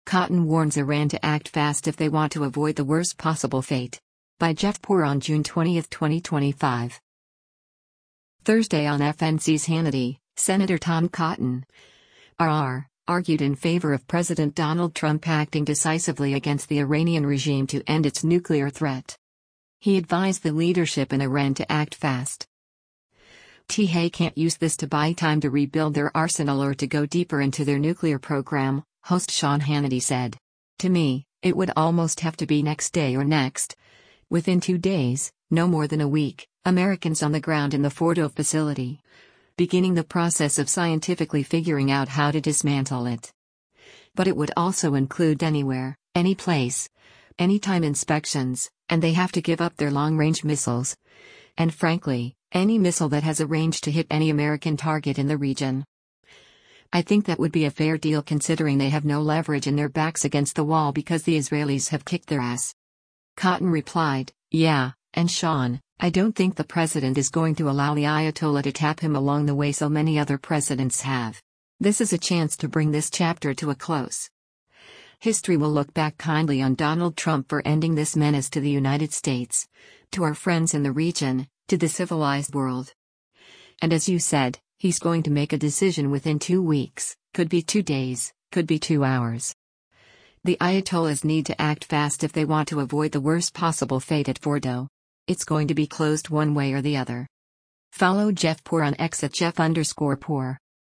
Thursday on FNC’s “Hannity,” Sen. Tom Cotton (R-AR) argued in favor of President Donald Trump acting decisively against the Iranian regime to end its nuclear threat.